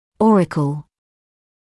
[‘ɔːrɪkl][‘оːрикл]ушная раковина